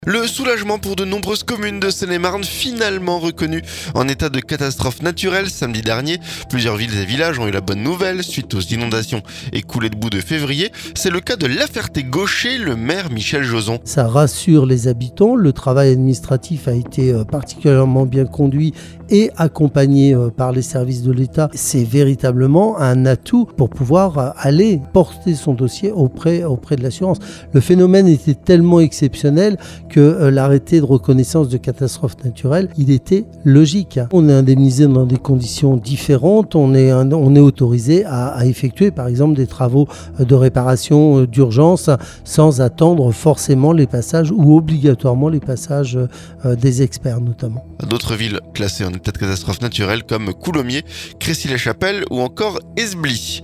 Micro tendu au maire Michel Jozon.